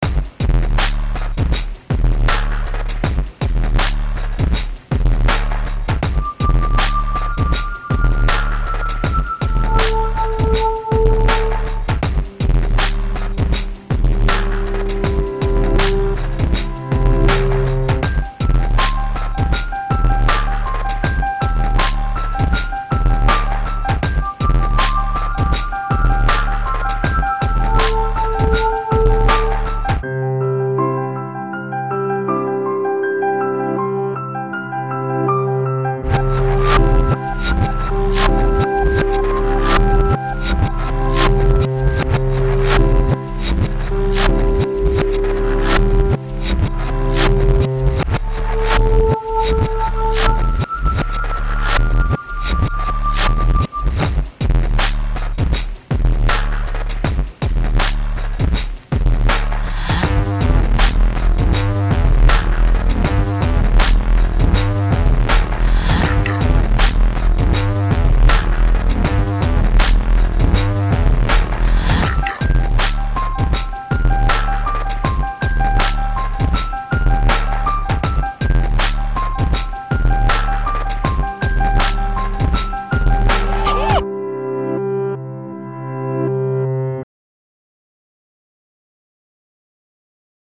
Vocals, Art Direction, Band
Synthesizer, L.S.I., Band
Keyboards, Uillean Pipes, Band